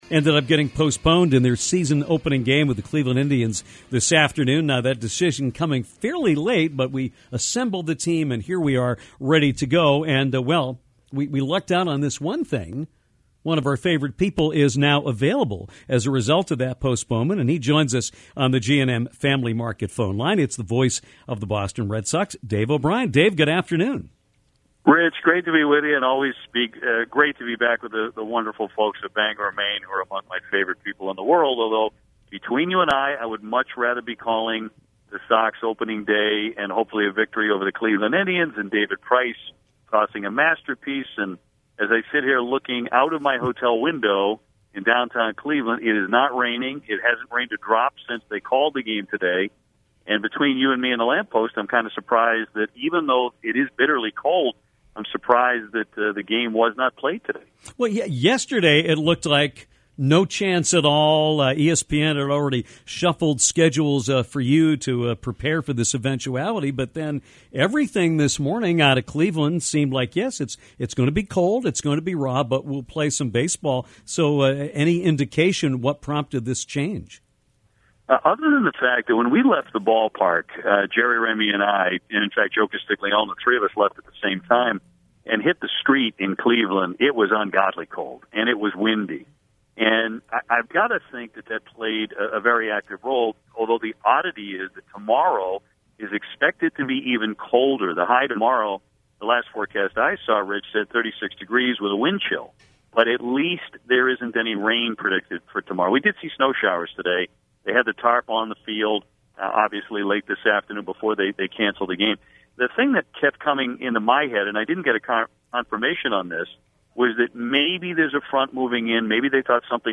Dave O’Brien, the voice of the Boston Red Sox on NESN, joined Downtown from Cleveland to talk about the Opening Day “Rain” out and previewed the 2016 edition of the Sox.